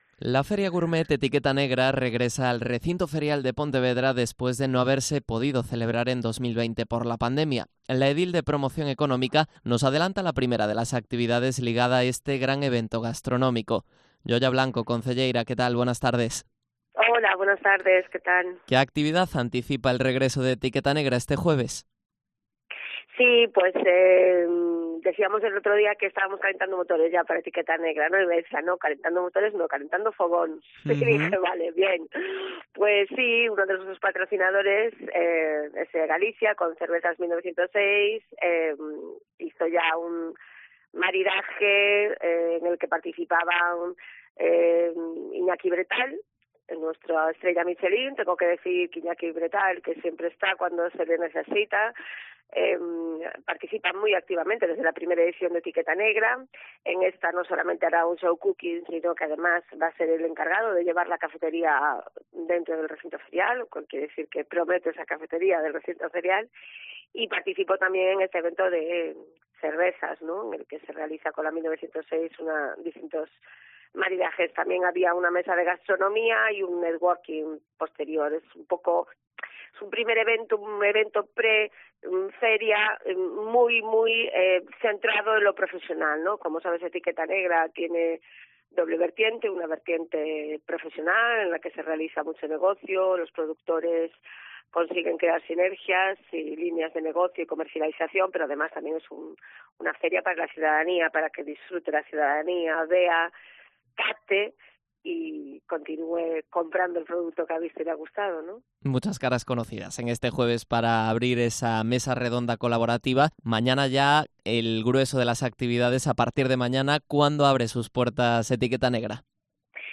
Entrevista a Yoya Blanco, edil de Promoción Económica de Pontevedra